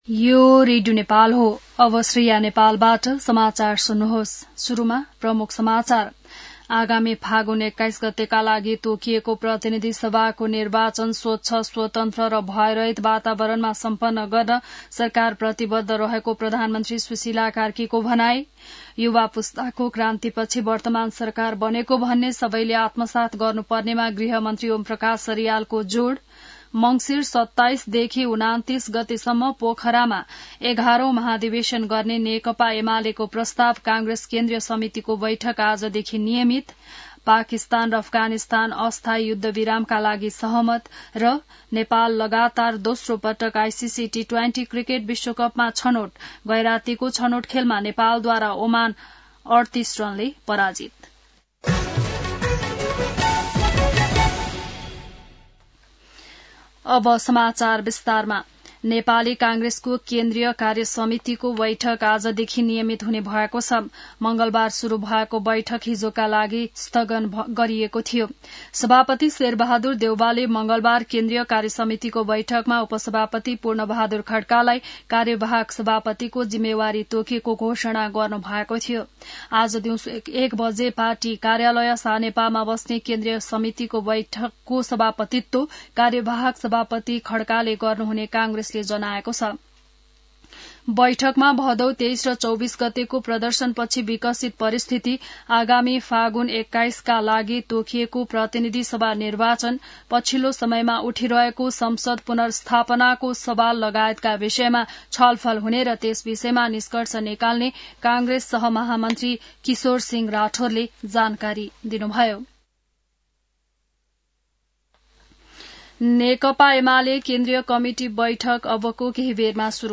बिहान ९ बजेको नेपाली समाचार : ३० असोज , २०८२